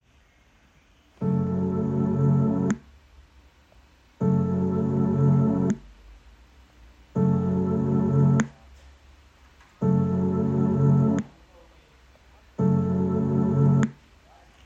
I confirm the feed-forward configuration didn’t solve the plop issue. I’m attaching an audio file for reference, where you can hear the artifacts each time playback stops.
Plop-sounds.mp3